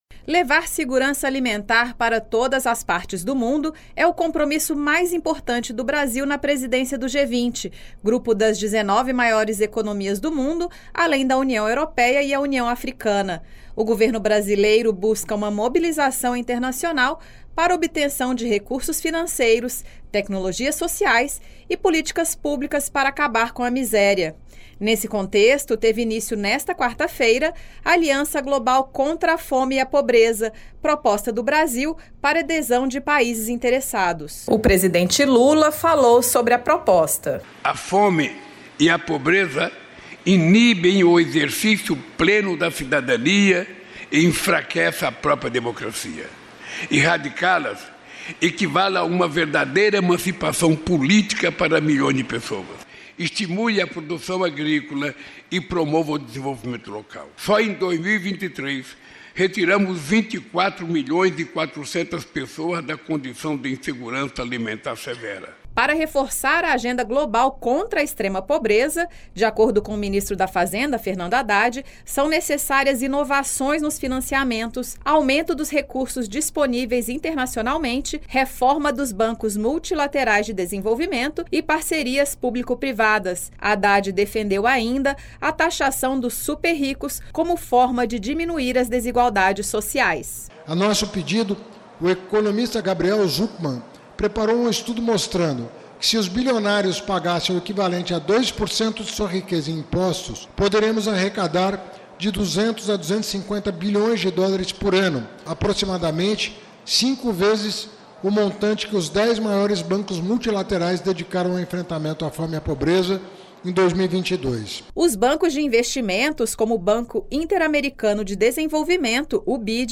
Matérias da Voz